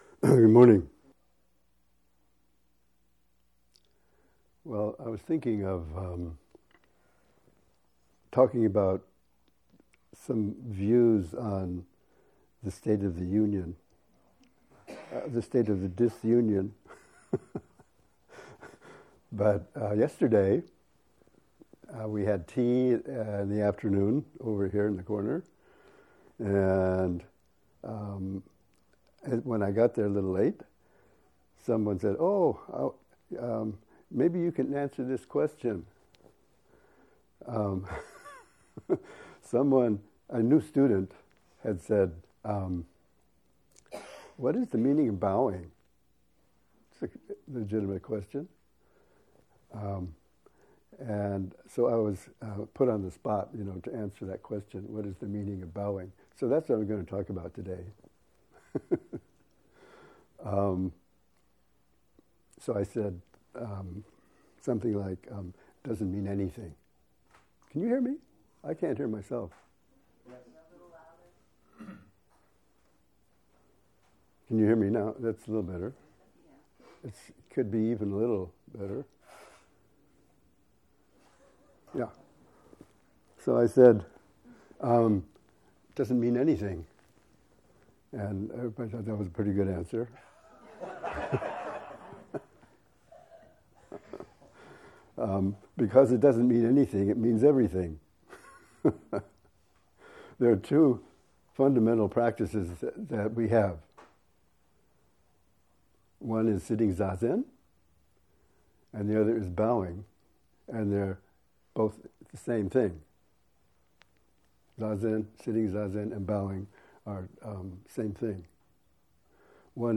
RSS 2016 Dharma Talk